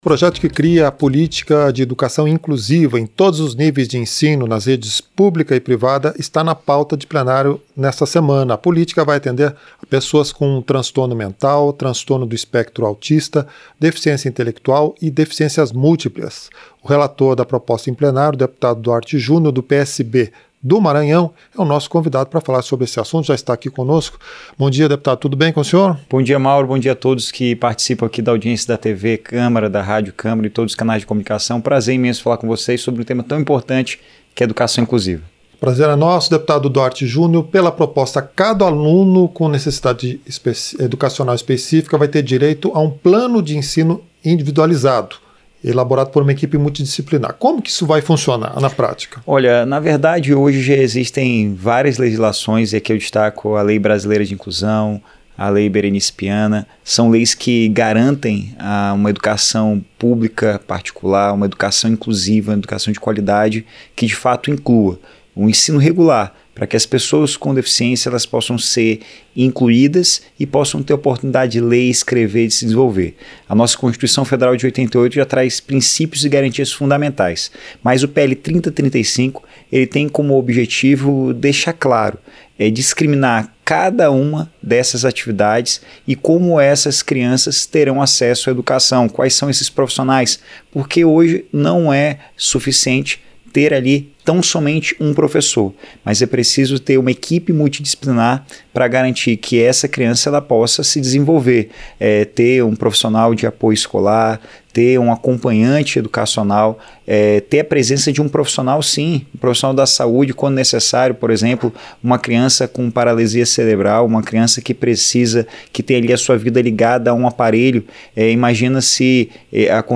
Entrevista - Dep. Duarte Jr. (PSB-MA)